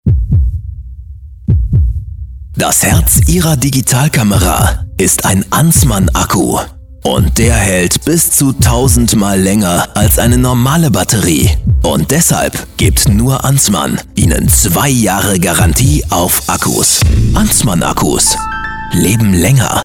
Stimme mit Wiedererkennungswert, großer Flexibilität und Zuverlässigkeit. Eigenes Studio mit MusicTaxi und Aptx.
Sprecher deutsch.
Sprechprobe: Industrie (Muttersprache):
german voice over artist